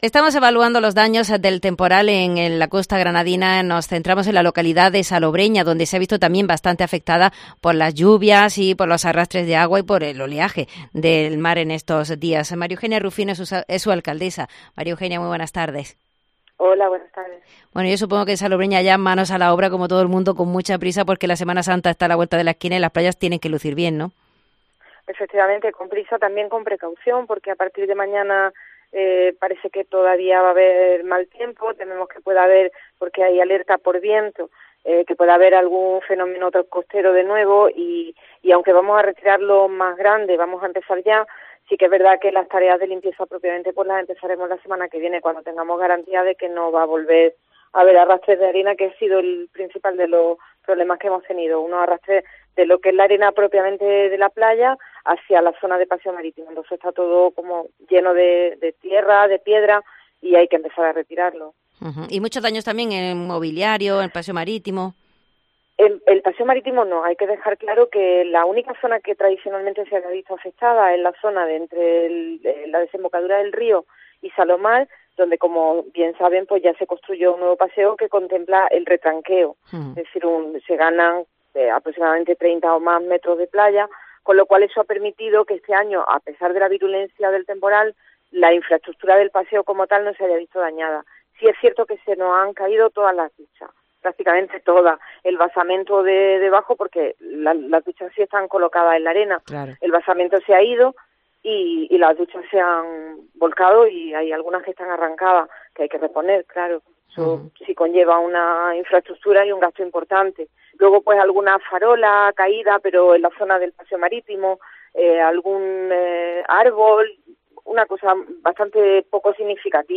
La alcaldesa de Salobreña, María Eugenia Rufino, nos habla sobre los daños que ha causado el temporal en las playas y de la posible solución ante las inundaciones en la zona baja de la localidad con las lluvias